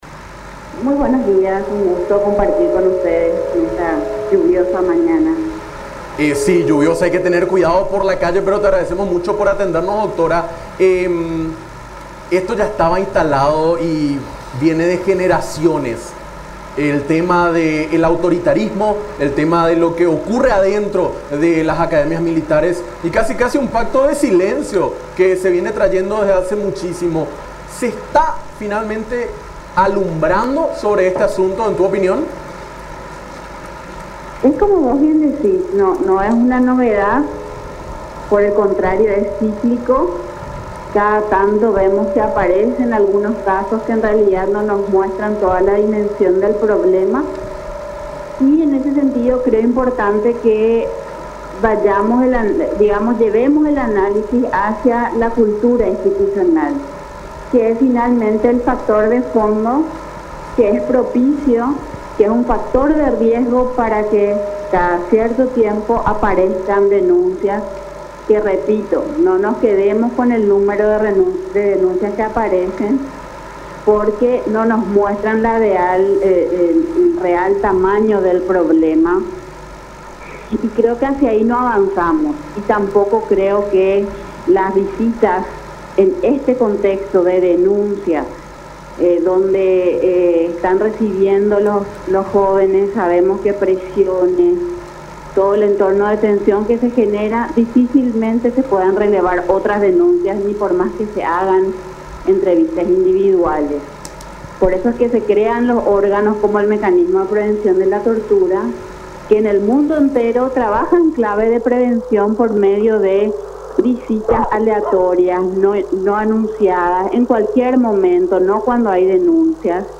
en diálogo con Nuestra Mañana a través de Unión TV